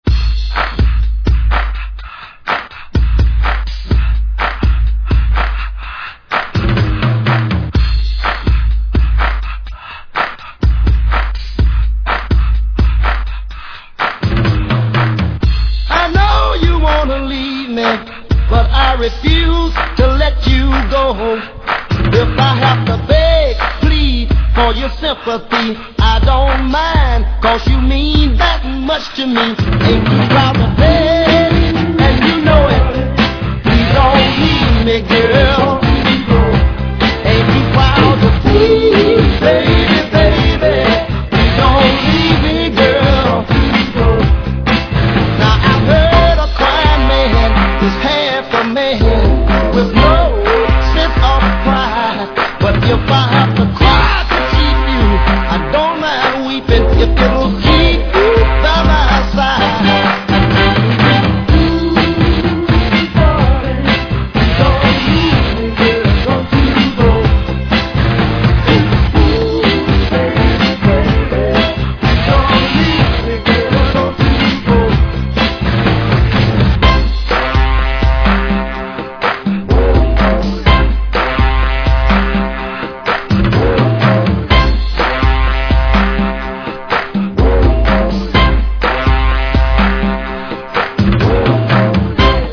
Essential throwback remix here!
Dancefloor tested & approved!